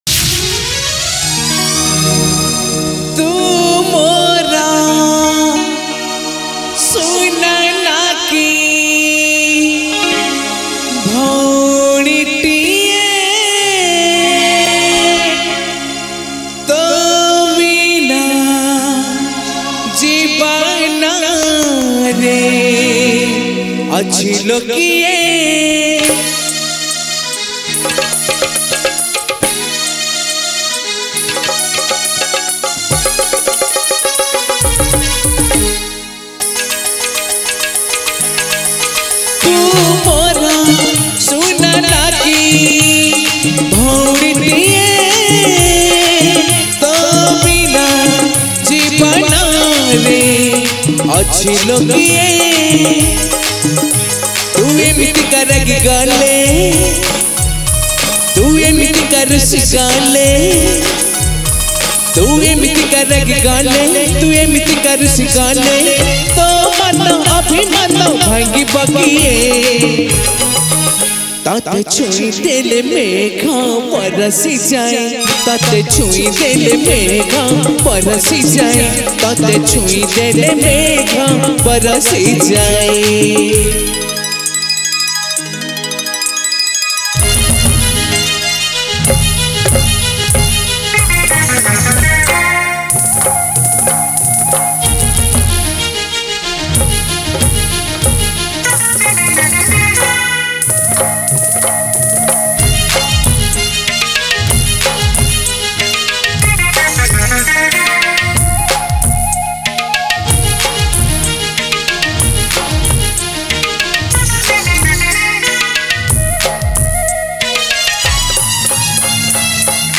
2017)Odia Jatra Song Songs Download